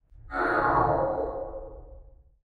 creatures_ghost.2.ogg